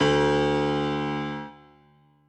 b_basspiano_v127l1o3d.ogg